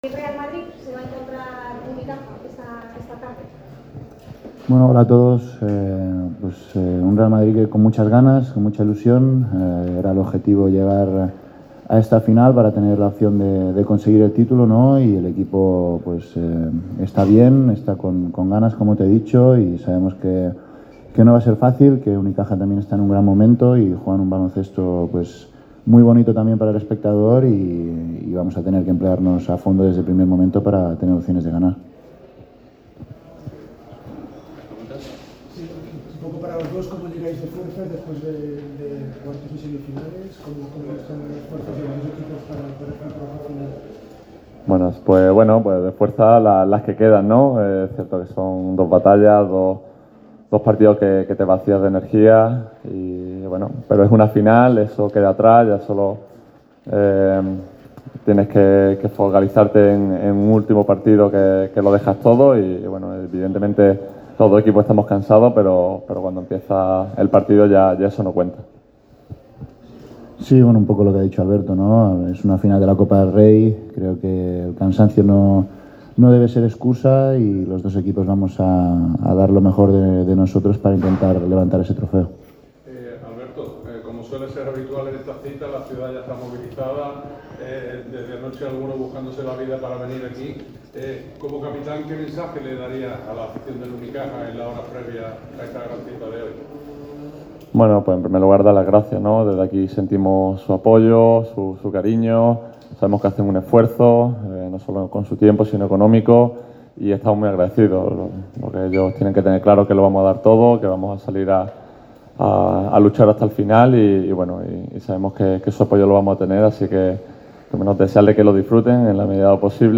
El capitán del Real Madrid, al igual que lo ha hecho Alberto Díaz, ha hablado frente a los medios en la previa a la final de Copa del Rey 2025. Los andaluces y madrileños se medirán en un duelo más que intenso esta tarde, a partir de las 20:00 horas.